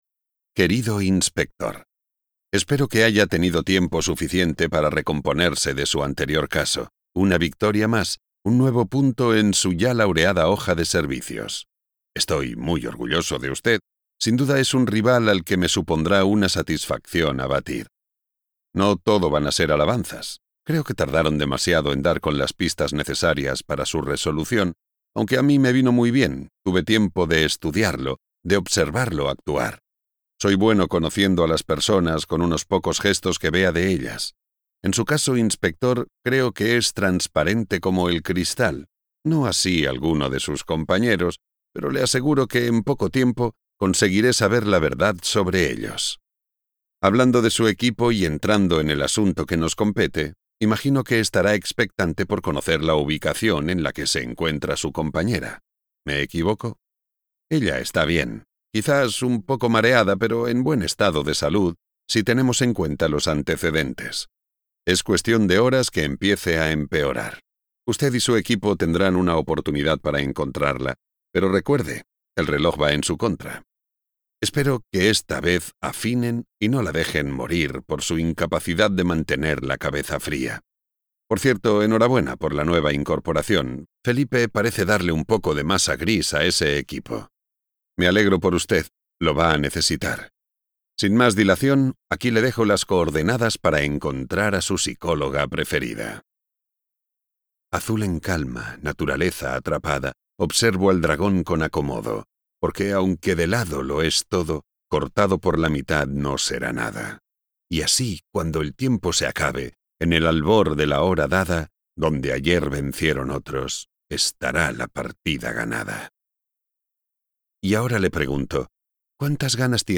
Audiolibro Enigmas para un rey (Engimas for a King)